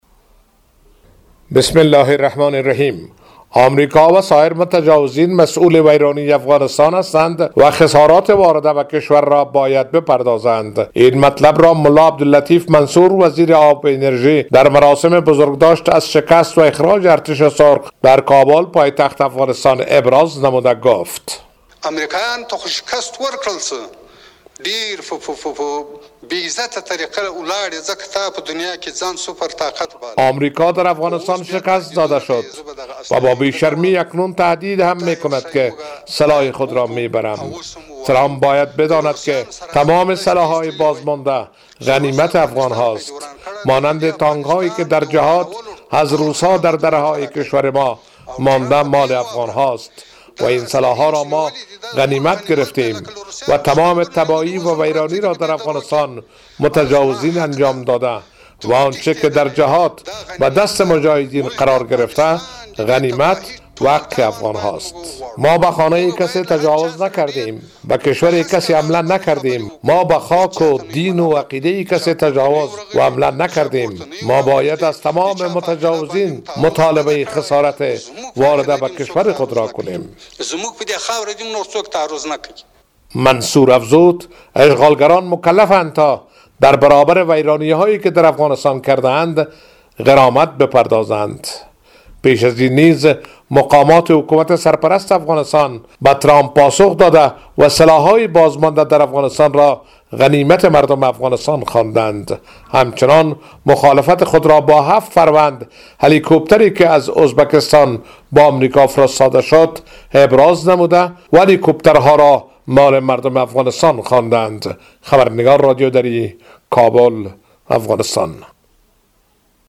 وزیر آب و انرژی حکومت طالبان در مراسم سالروز خروج ارتش سرخ شوروی از افغانستان گفت: ترامپ باید بداند که تمامی سلاح های بازمانده از آمریکایی‌ها، غنیمت افغان...